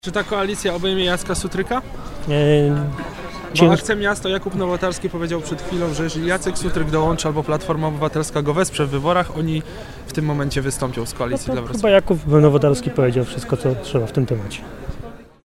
„Koalicja dla Wrocławia” ma działać pod jednym warunkiem – że nie dołączy do niej prezydent Jacek Sutryk. Tak sprawę komentuje szef Platformy.